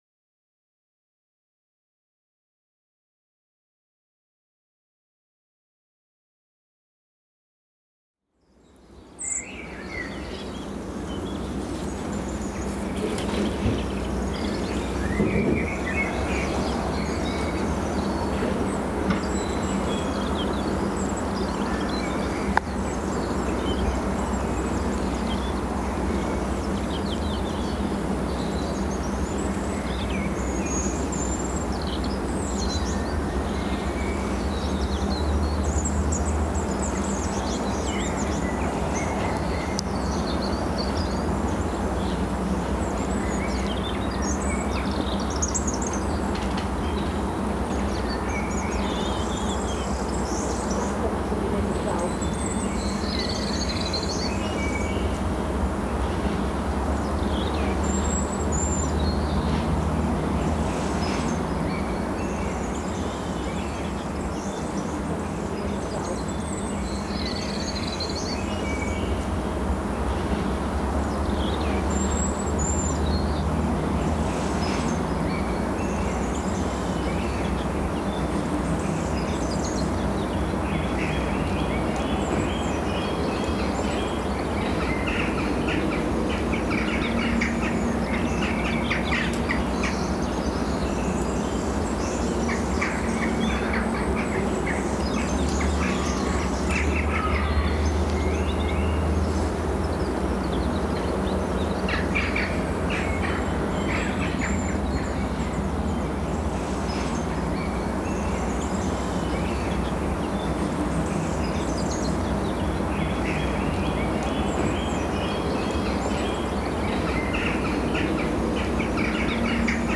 AMB_Intro_Ambience_R.ogg